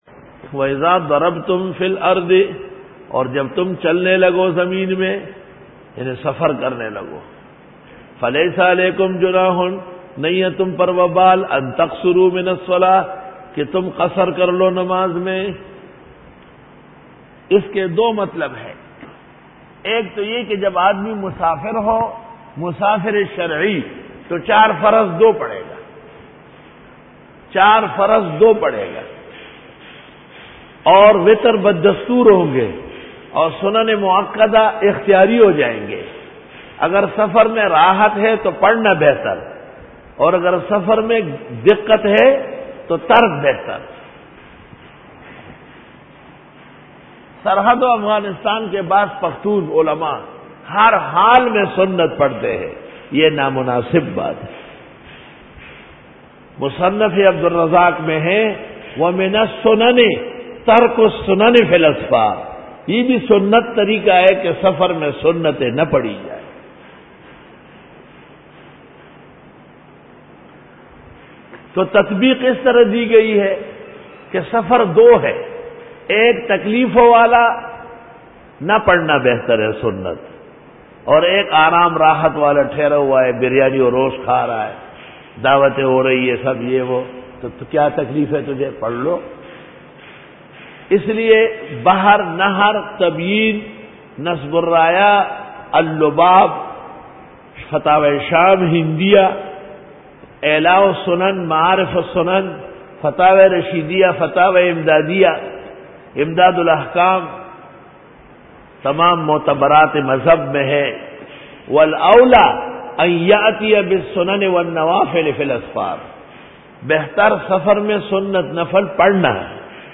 Dora-e-Tafseer 2009